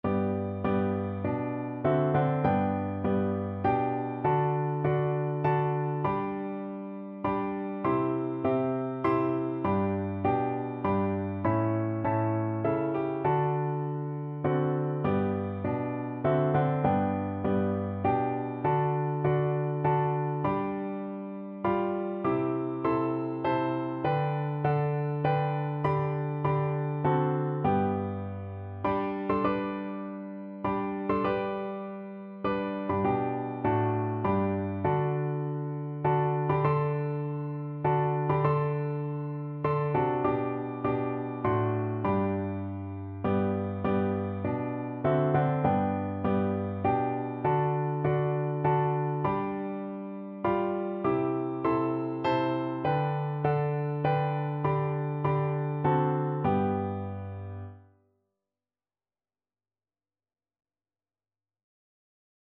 Traditional William H. Doane To God Be the Glory (Doane) Piano Four Hands (Piano Duet) version
Christian Christian Piano Four Hands (Piano Duet) Sheet Music To God Be the Glory (Doane)
3/4 (View more 3/4 Music)
G major (Sounding Pitch) (View more G major Music for Piano Duet )
Traditional (View more Traditional Piano Duet Music)